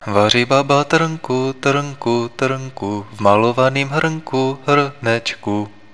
Signal was compressed and than decompressed using this method.
At the 38 percent compression (the compression treshold obtained by denoising alogithm) there is no signal corruption seen.